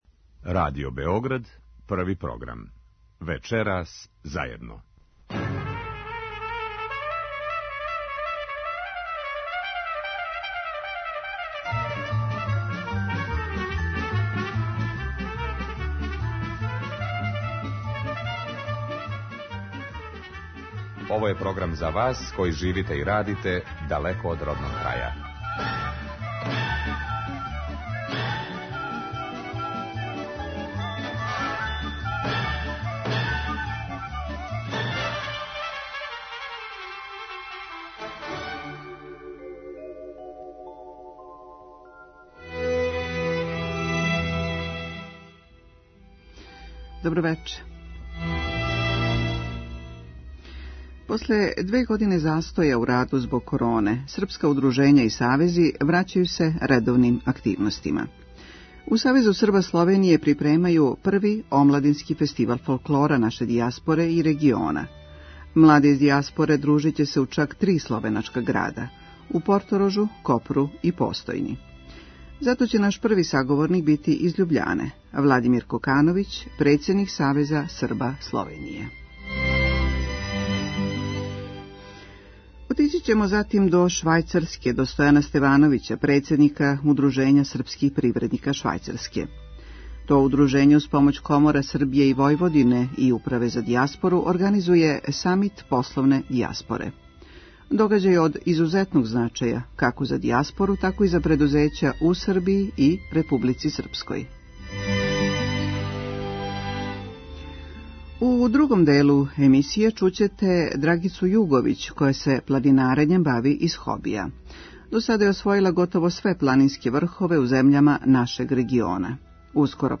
Емисија магазинског типа